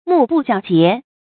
目不交睫 注音： ㄇㄨˋ ㄅㄨˋ ㄐㄧㄠ ㄐㄧㄝ ˊ 讀音讀法： 意思解釋： 交睫：上下睫毛相交接，即閉眼。